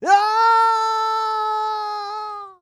Male_Falling_Shout_02.wav